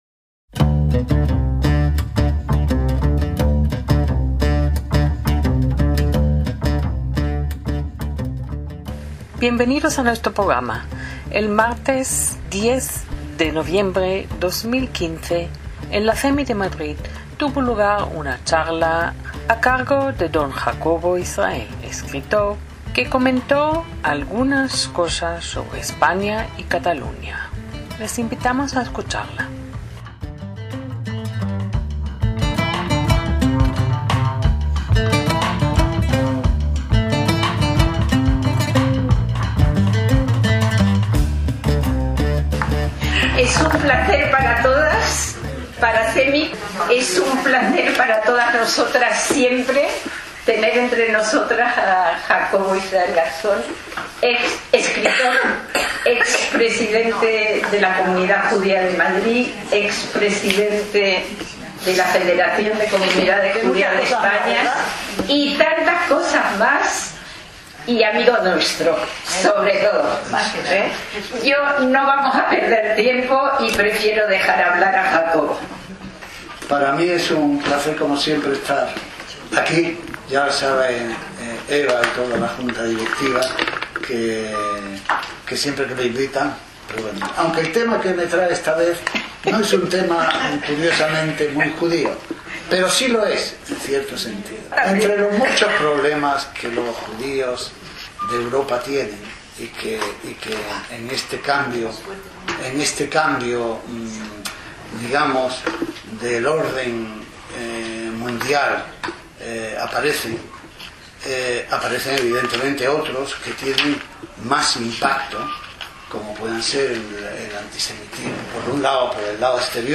ACTOS EN DIRECTO - Hoy os ofrecemos un "2 por 1" en charlas breves en la CEMI de Madrid.